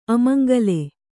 ♪ amaŋgale